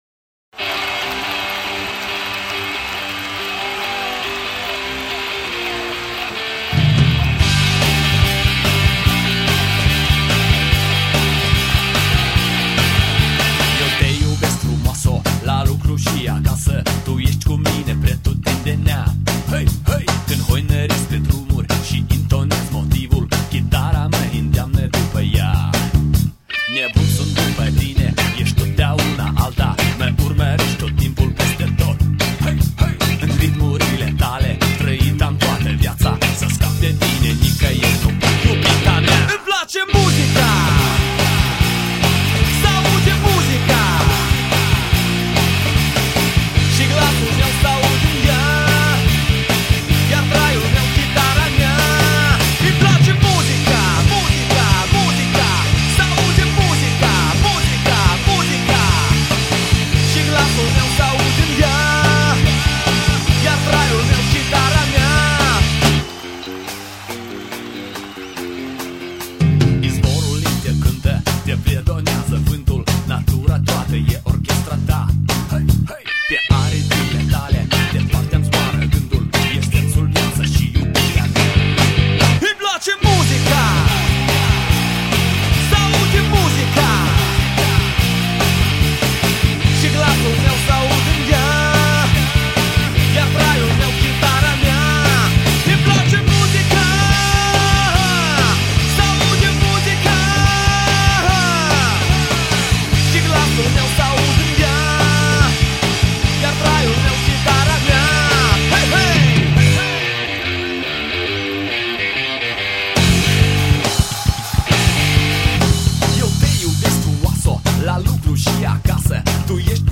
там явный фольк, но обхардкоренный.
Нирвана слышится?